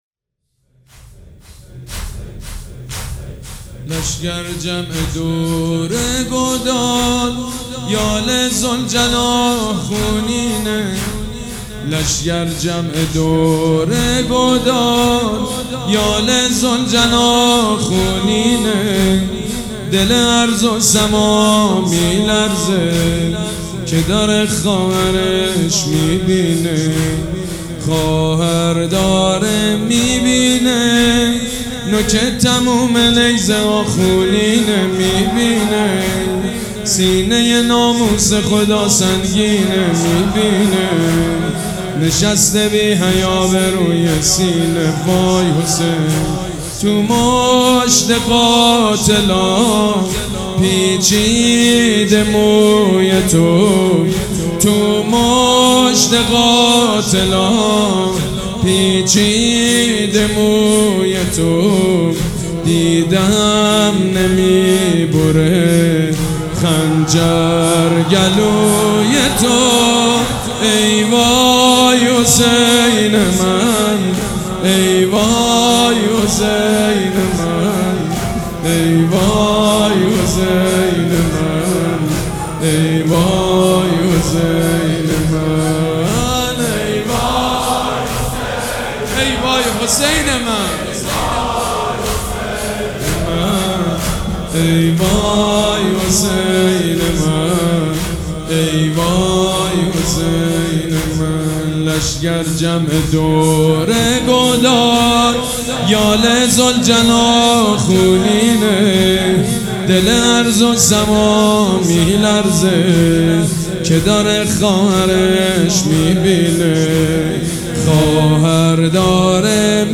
مراسم عزاداری شب چهارم